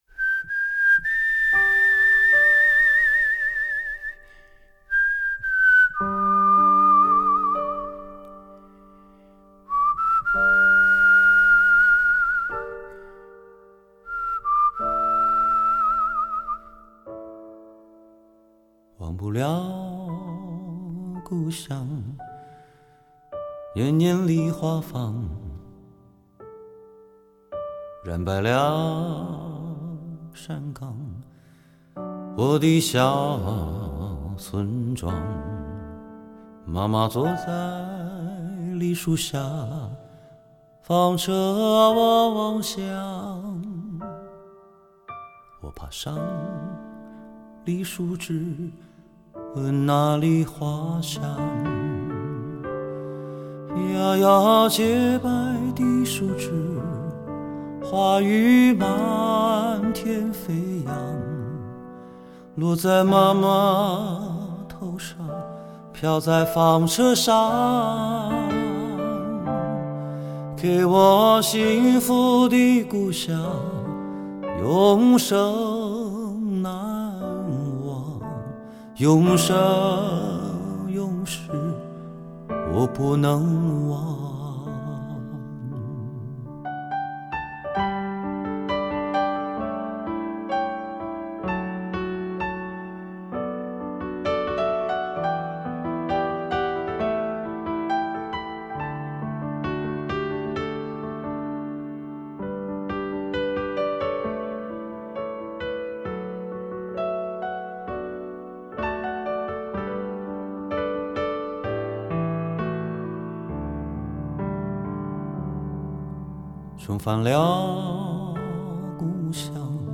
吉他荡漾、风琴隐约
陶笛悠远、钢琴缠绵